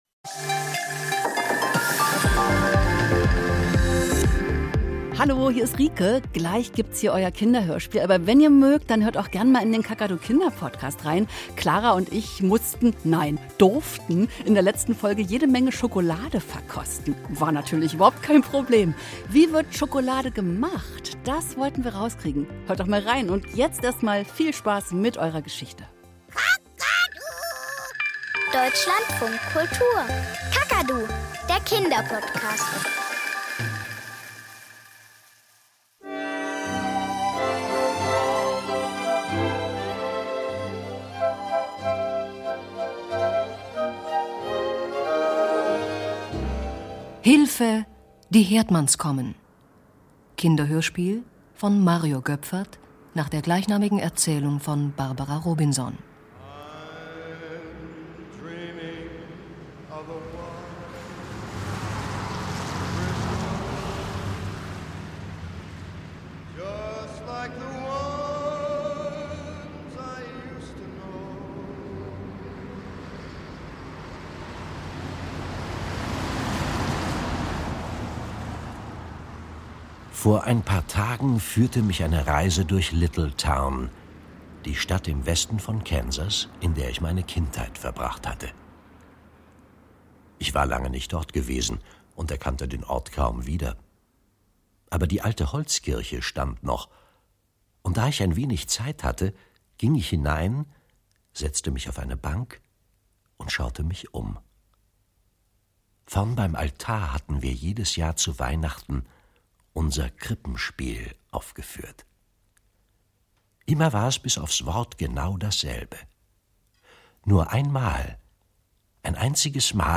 Kinderhörspiel - Hilfe, die Herdmanns kommen